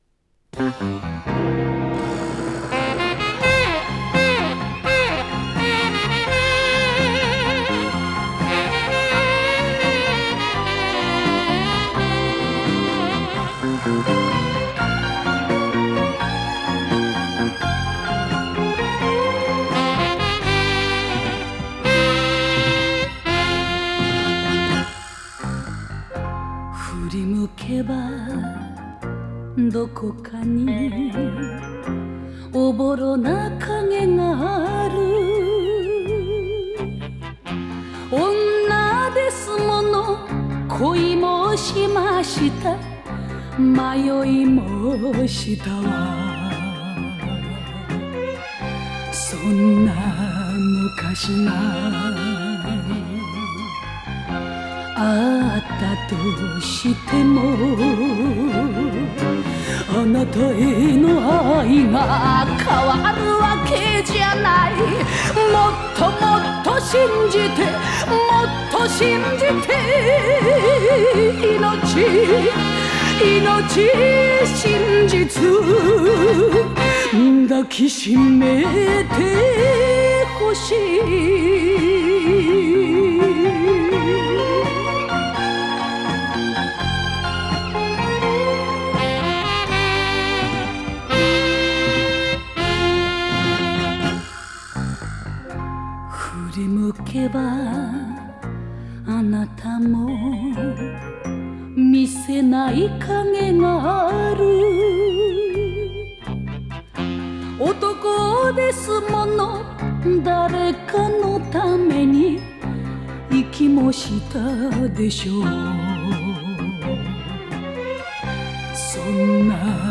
picЖанр: Enka